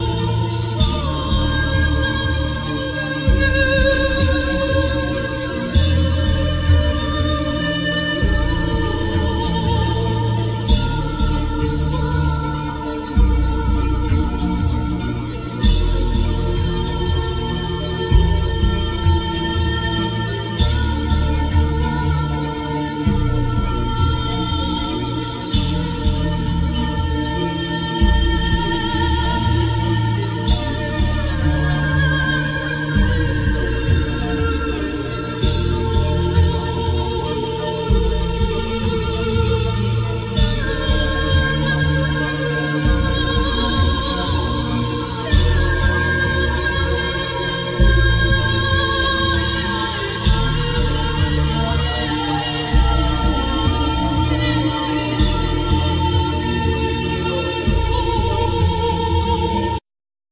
Voice
Santoor,Balalaika,Mandolin,Keyboards,Dulcimer,Shaker,